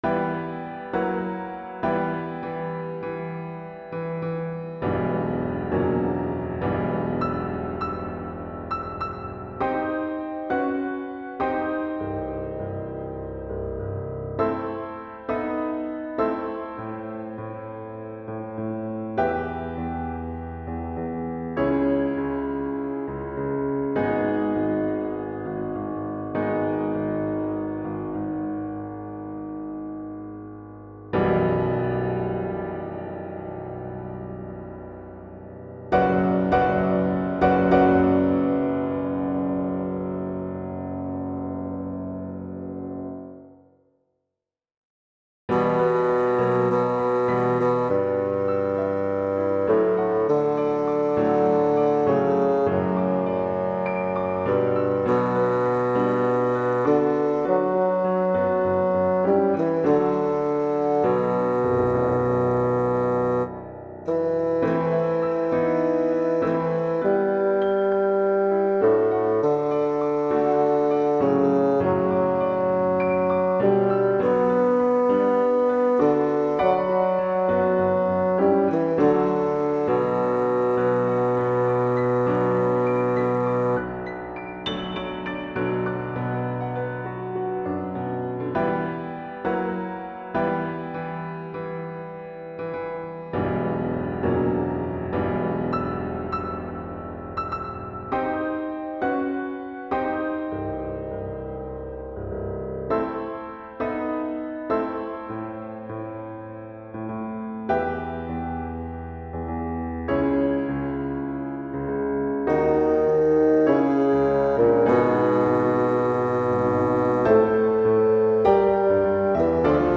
Demoaufnahme 8/27
Tonart: h-moll / Tempo: Andante
- In der Demoaufnahme wurde die Gesangstimme durch ein Fagott ersetzt.